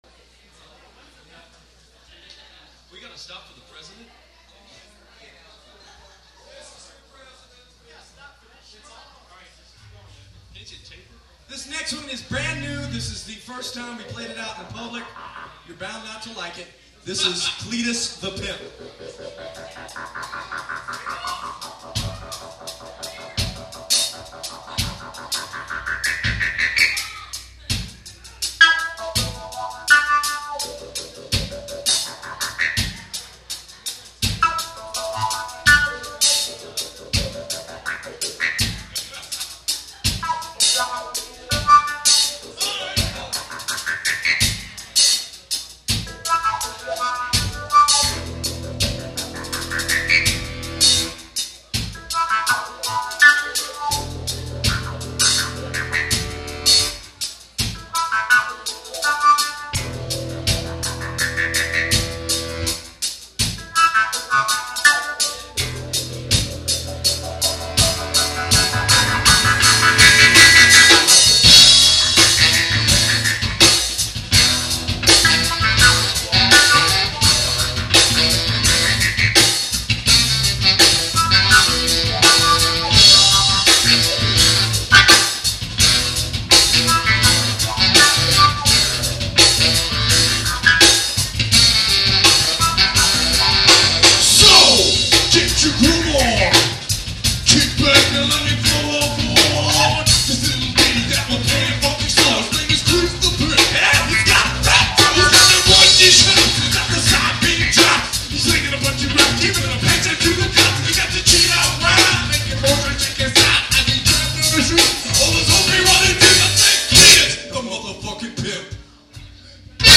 funk rock